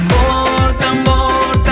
Play, download and share tambor tambor original sound button!!!!